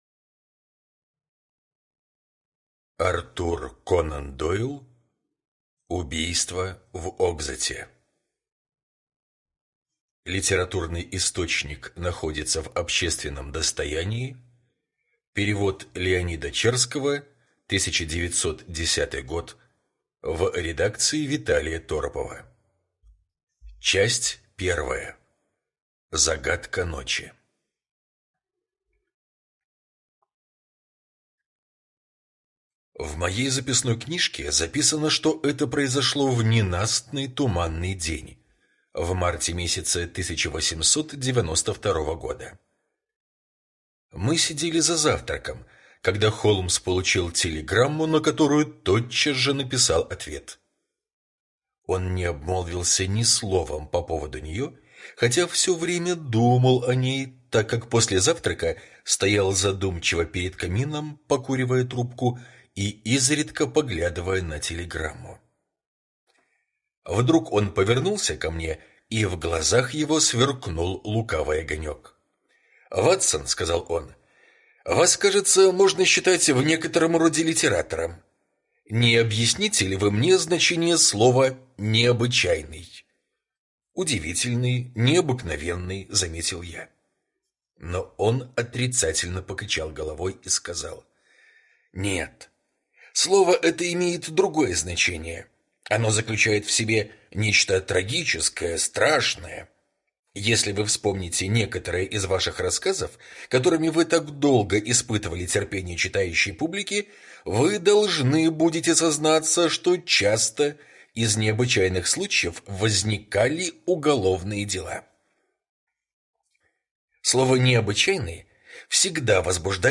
Убийство в Окзотте — слушать аудиосказку Артур Конан Дойл бесплатно онлайн